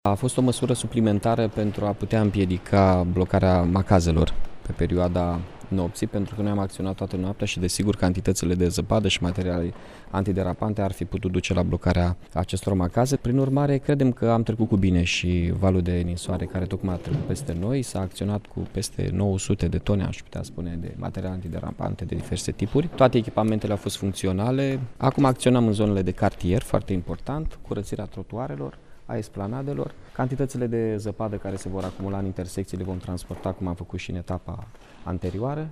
Primarul Iașului, Mihai Chirica spune că deszăpezirea în municipiu s-a făcut treptat și nu au fost probleme deosebite.
9-feb-ora-15-Mihai-Chirica-deszapezire.mp3